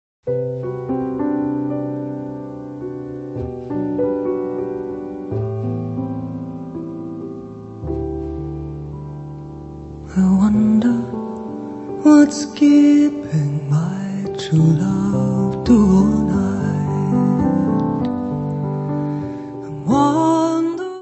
voz
acordeão diatónico
clarinete, saxofone tenor, saxofone soprano
contrabaixo
Music Category/Genre:  World and Traditional Music